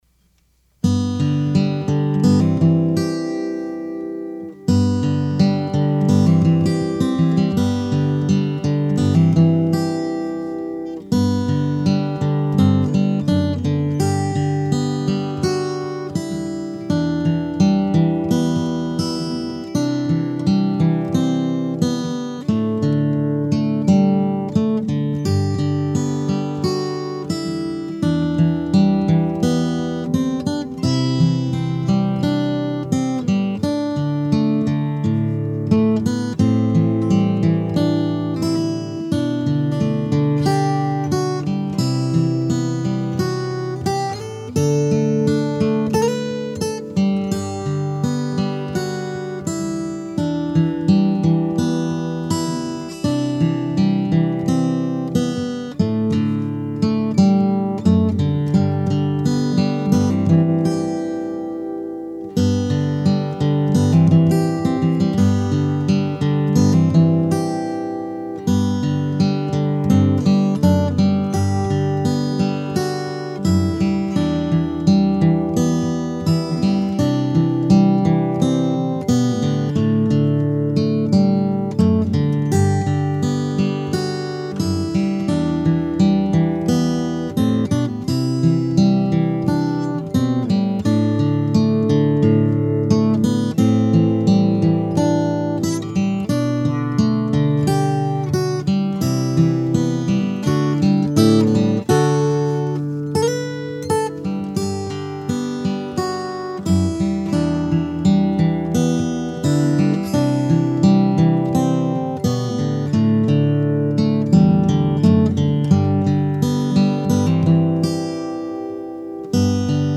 This year it seemed like it was about time to “present” you with the instrumental that I wrote almost half of your lifetime ago and titled in honor of the day of your birth.
A very sweet and loving birthday tune.
What a beautifully heartwarming instrumental!